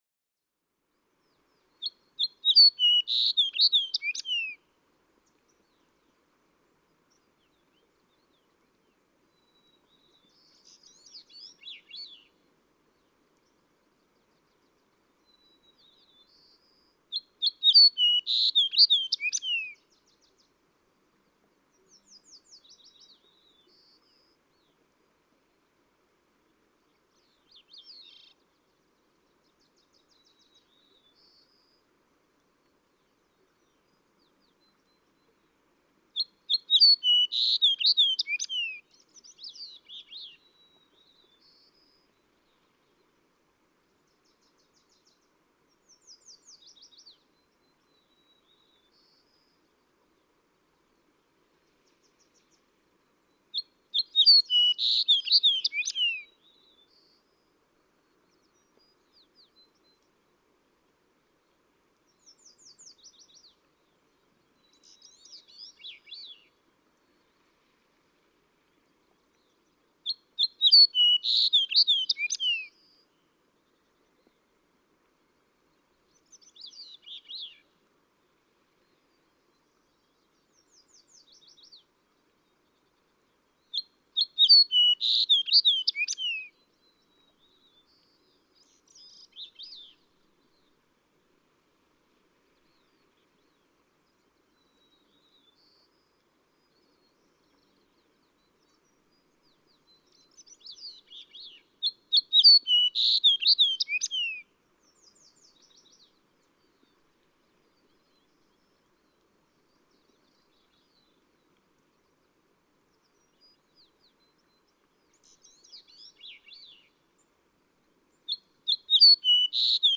Fox sparrow
The "red" fox sparrow:
590_Fox_Sparrow.mp3